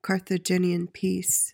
PRONUNCIATION:
(kar-thuh-JIN-ee-uhn pees)